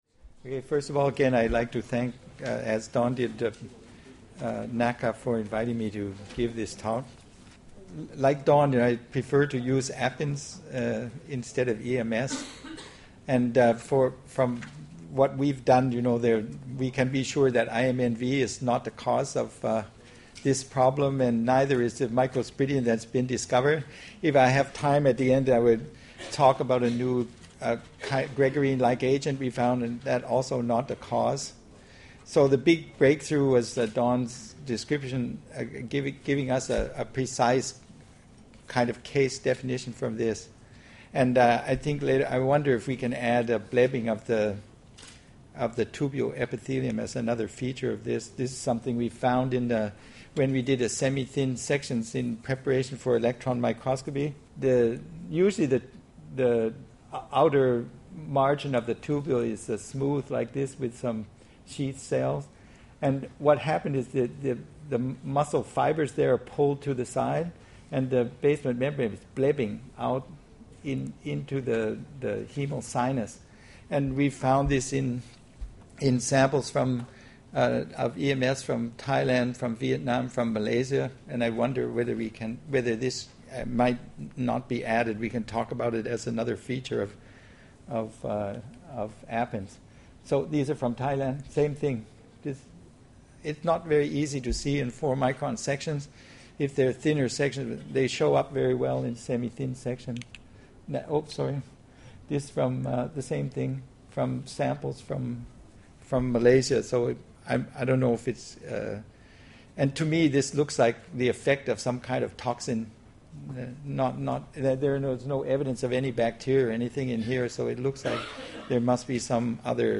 Presentation on research progress into the bacterial or viral causes of AHPNS.